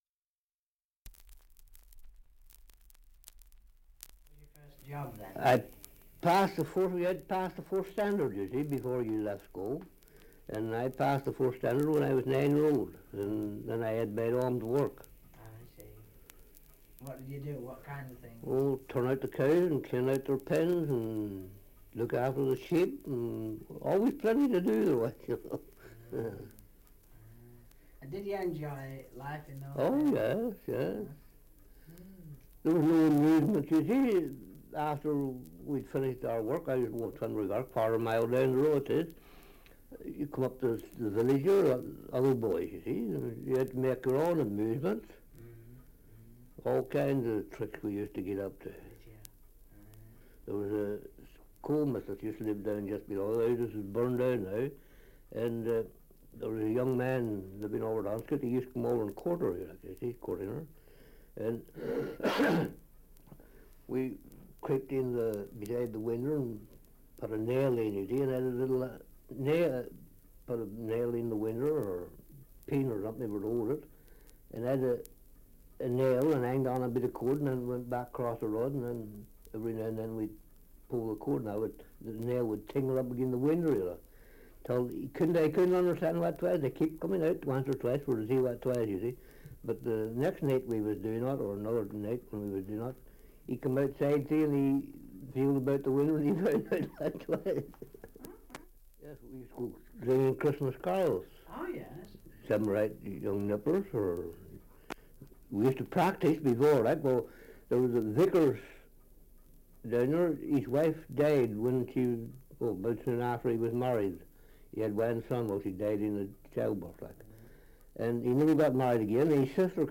2 - Survey of English Dialects recording in Wootton Courtenay, Somerset
78 r.p.m., cellulose nitrate on aluminium